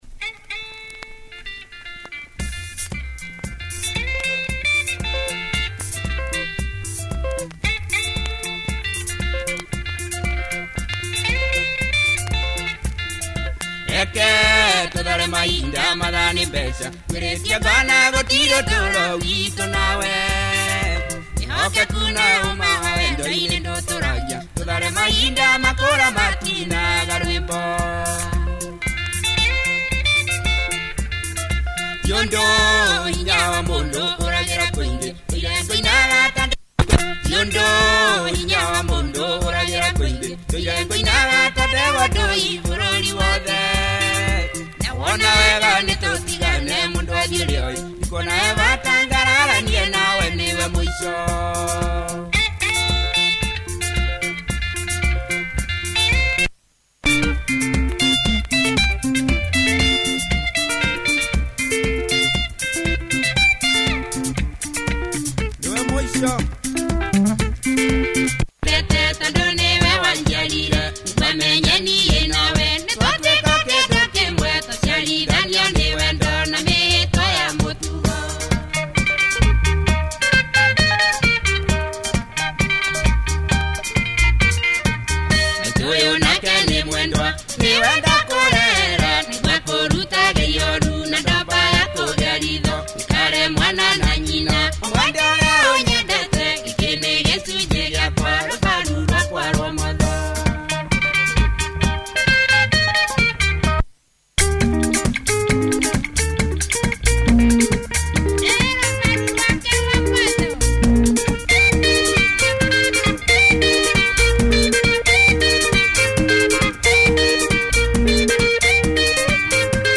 Good straight bouncy Benga, nice vocals, clubby groovy!